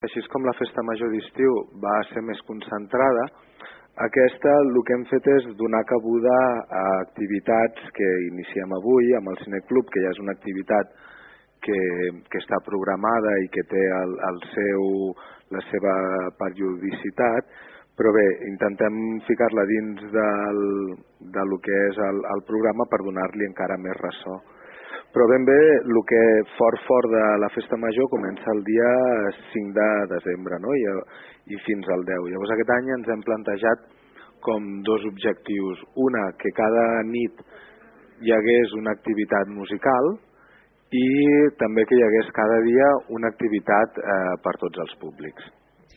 Aquesta setmana, el regidor de Cultura a l’Ajuntament de Malgrat de Mar, Paco Márquez, va passar pel programa Palafolls en Xarxa i va explicar quines amb quines propostes arriba la Festa de Sant Nicolau d’enguany.